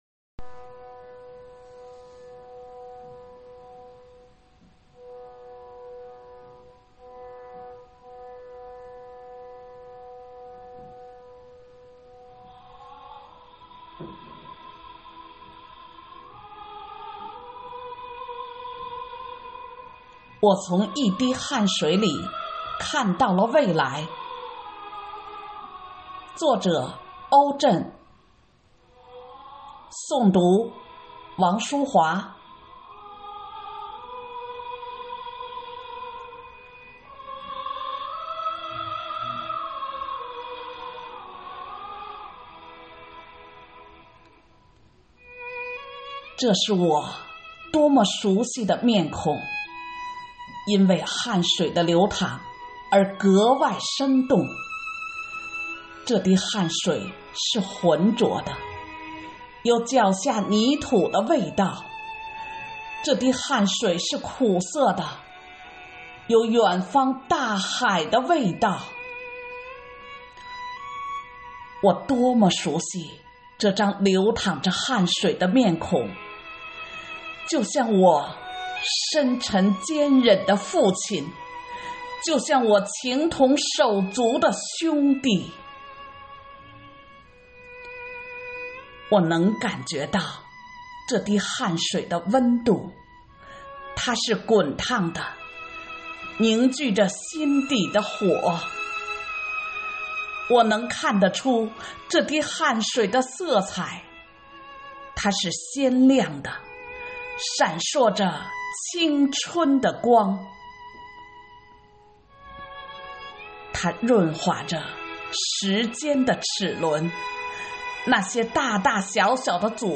朗诵